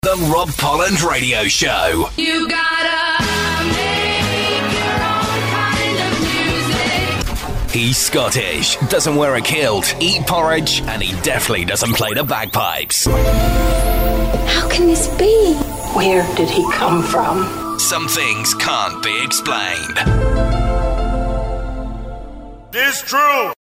It’s good old-fashioned radio entertainment!
rprs-main-intro.mp3